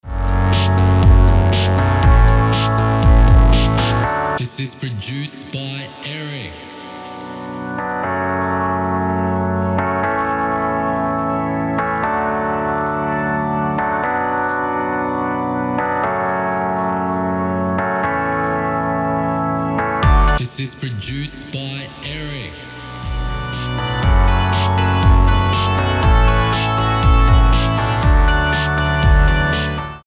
Completely synthetic.
Key: D Major Tempo: 120BPM Time: 4/4 Length: 2:56